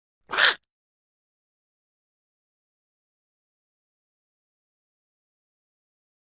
Duck Quack; Short Duck Quack.